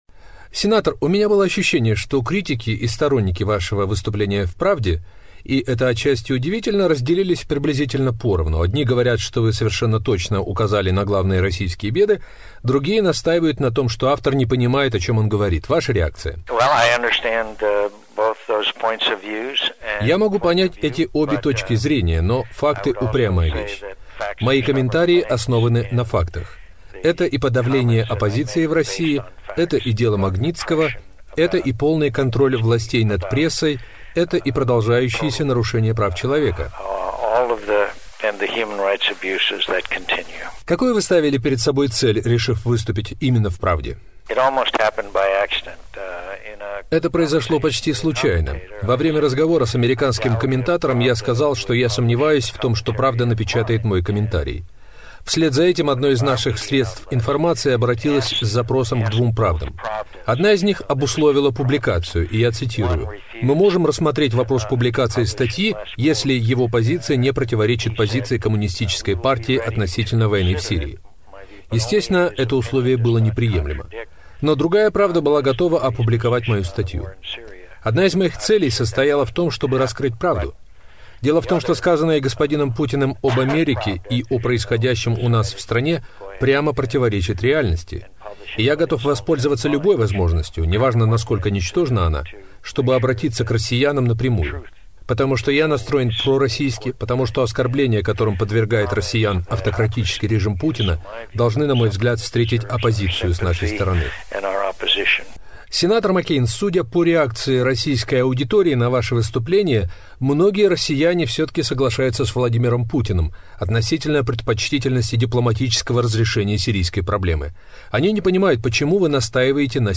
Интервью Джона Маккейна Радио Свобода